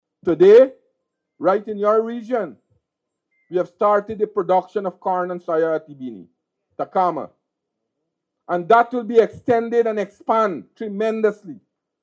Minister Mustapha, while addressing a crowd in Linden, Region 10, posited that the Government plans to transform agriculture in that Region, an which will result in Region 10 playing a crucial role in Guyana’s efforts to help the Caribbean achieve this 25 percent reduction.